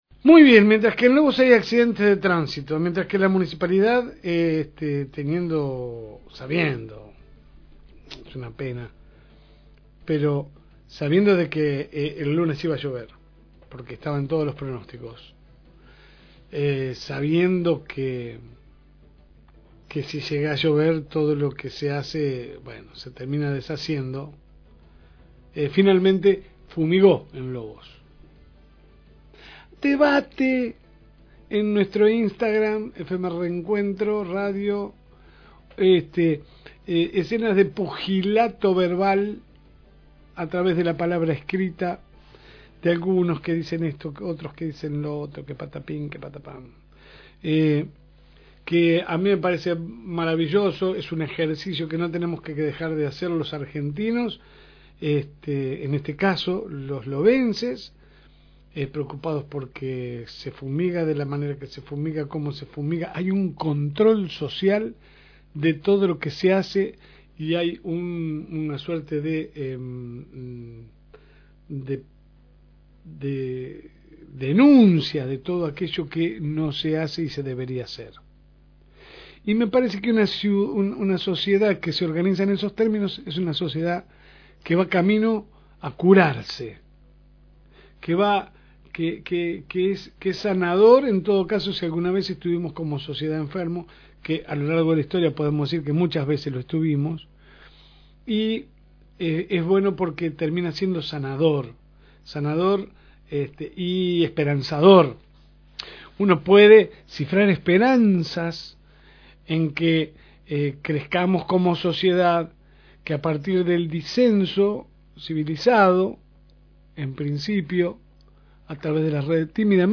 AUDIO – La editorial de todas las mañanas – FM Reencuentro
La Segunda Mañana sale por el aire de FM Reencuentro 102.9 de lunes a viernes de 10 a 12 HS.